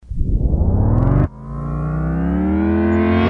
Snaretapeend
描述：I'm not sure how this one has been generated, but I think it was the end of an analogue 2" tape of a snare recording reversed during mixdown
标签： reel scratch snare analogue tape drumroll roll reverse drum
声道立体声